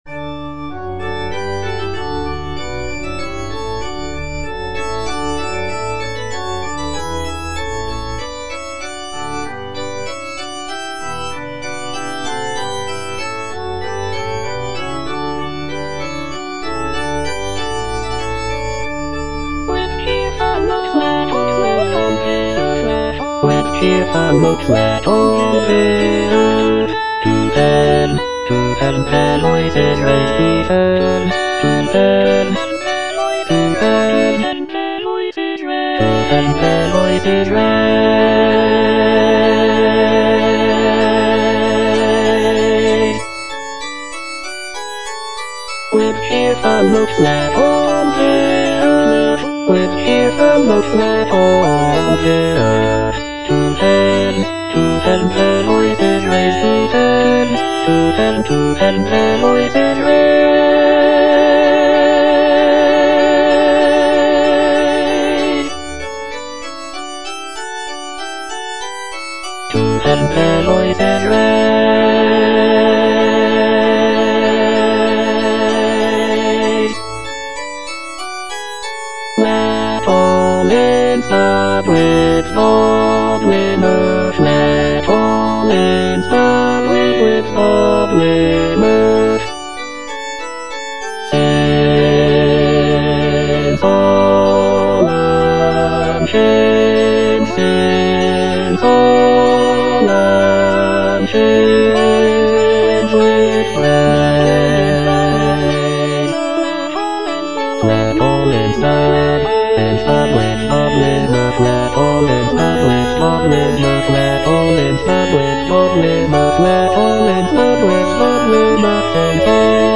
(A = 415 Hz)
Bass (Emphasised voice and other voices) Ads stop
sacred choral work